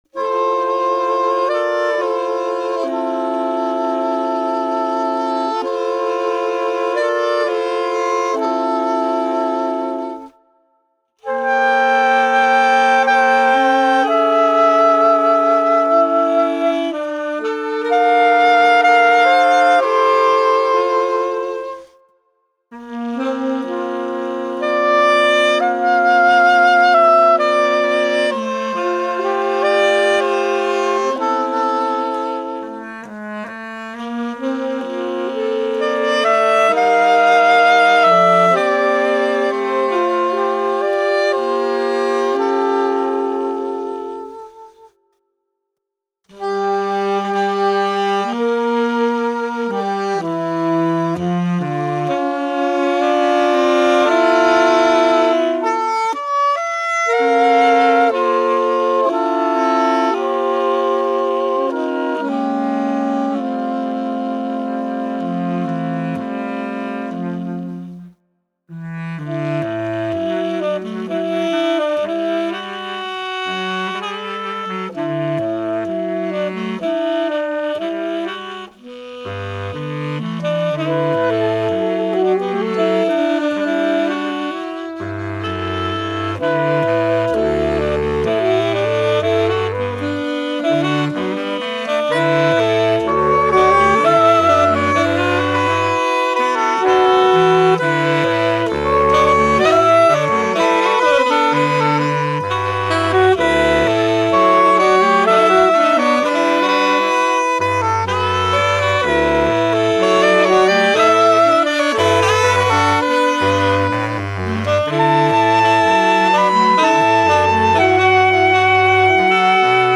Voicing: Saxophone Quartet